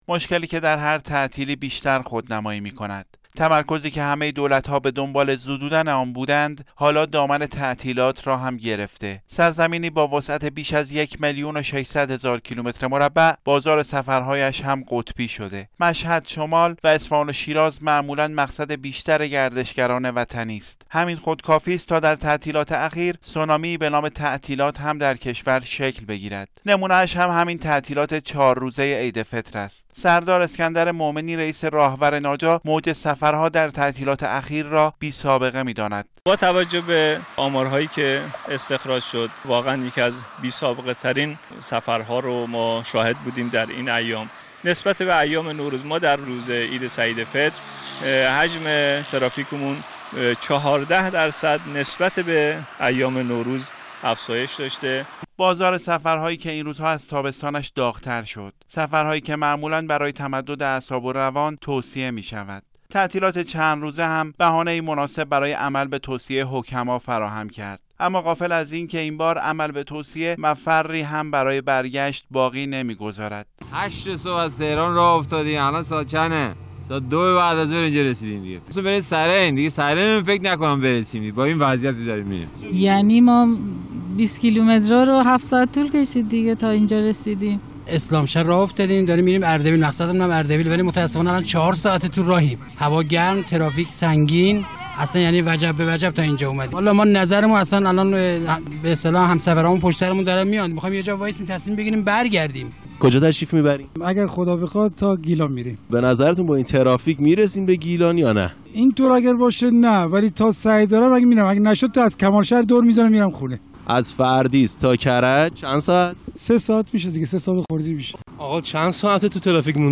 گزارش "شنیدنی" از سونامی سفر و کام مسافرانی که تلخ شد - تسنیم